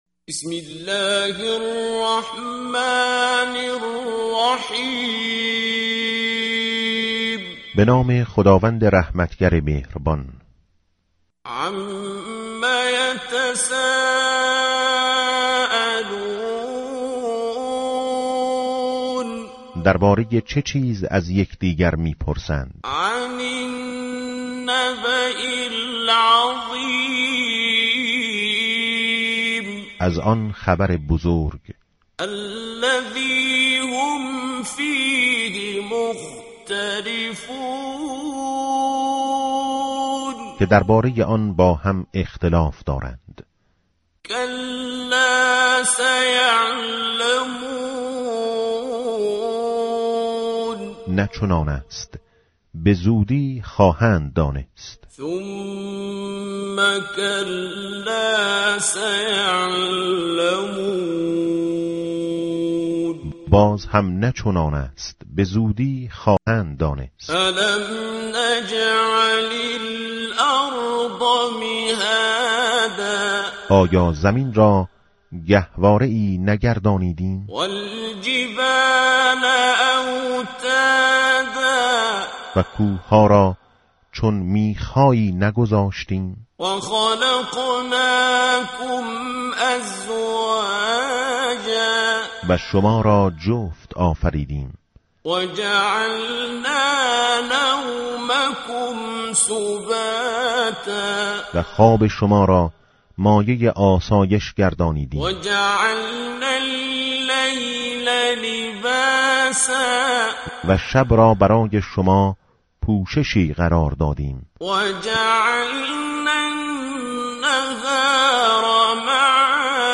سوره نباء تلاوت عبدالباسط عبد صمد